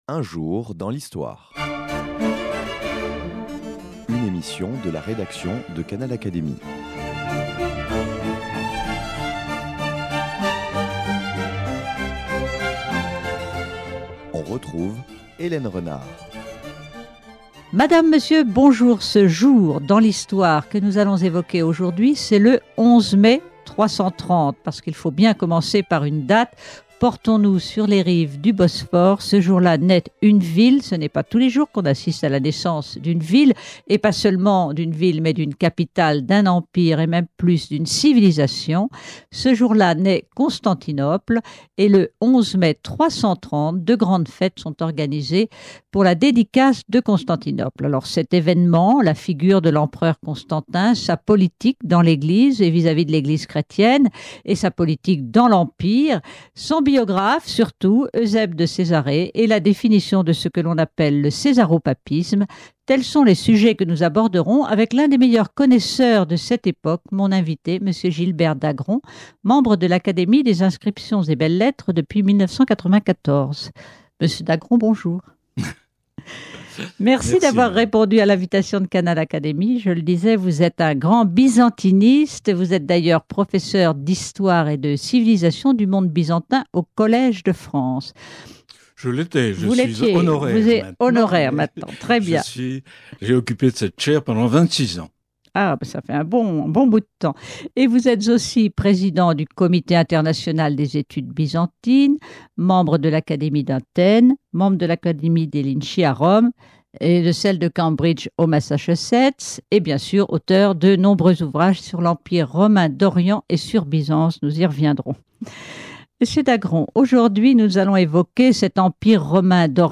Gilbert Dagron nous explique les relations entre le pouvoir impérial et le pouvoir religieux de l’Eglise au IVe siècle.